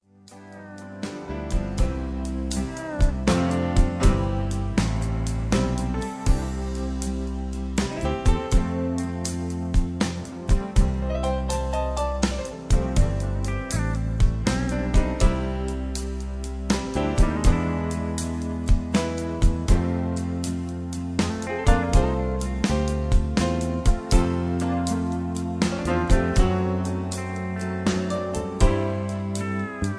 backing tracks
rock and roll